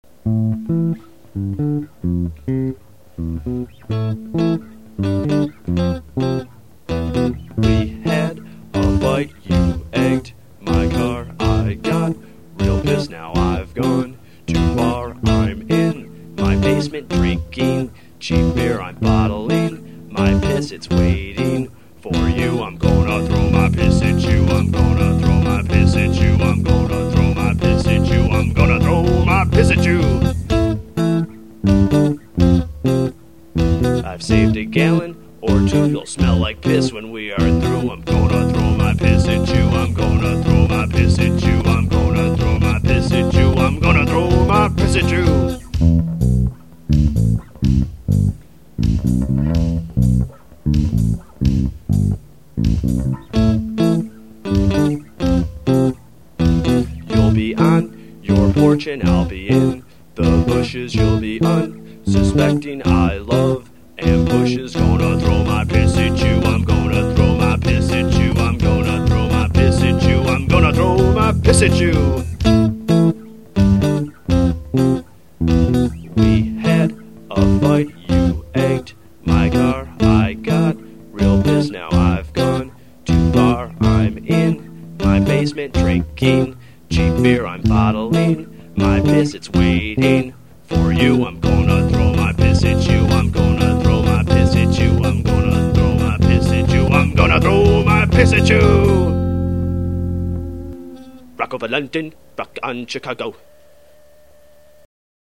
All Guitar done by ?
Drums